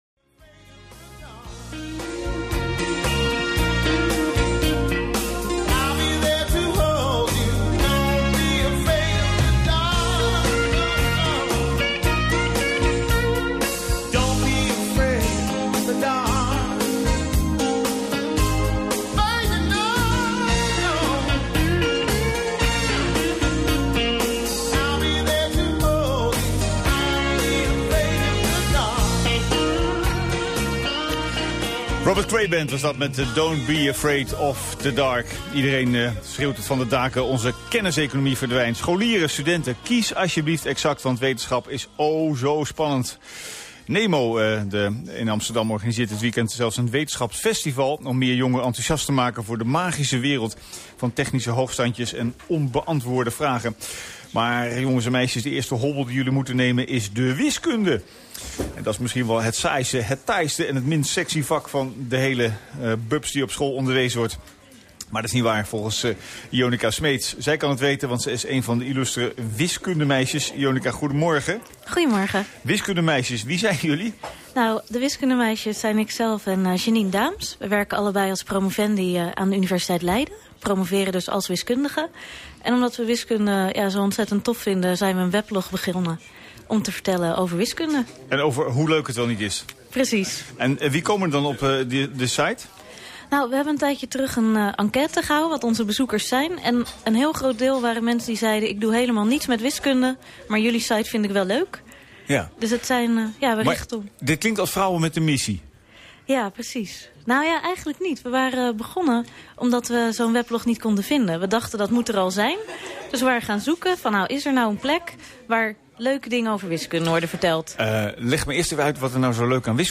Radio
Morgen (zaterdag de 17de) ben ik te gast bij Cappuccino en vertel ik waarom wiskunde zo tof is.